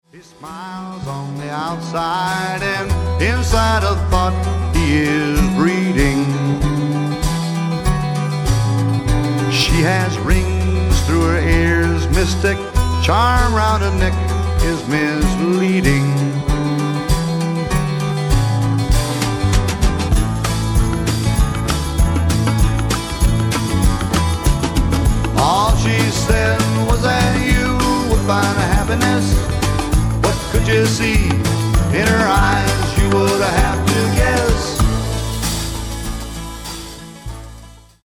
SSW / SWAMP ROCK